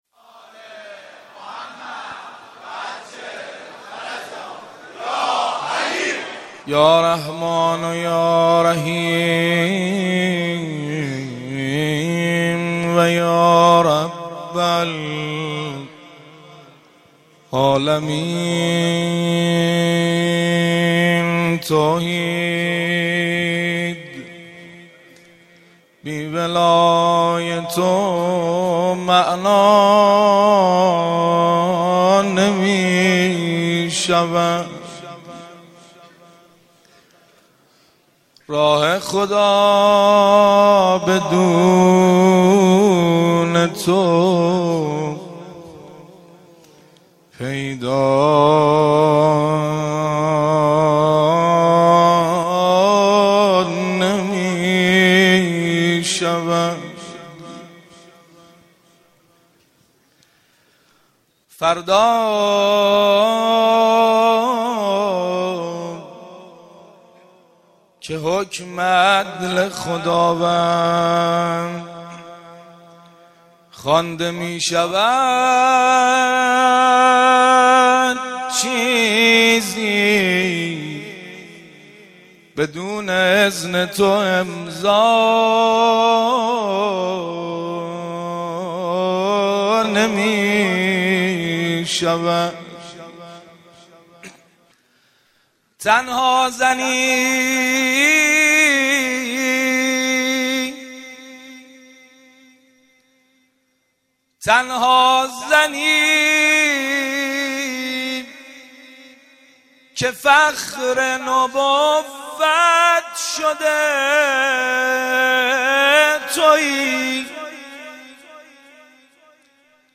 مناسبت : ولادت حضرت فاطمه‌ زهرا سلام‌الله‌علیها
قالب : مدح